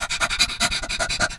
pgs/Assets/Audio/Animal_Impersonations/dog_2_breathig_01.wav at master
dog_2_breathig_01.wav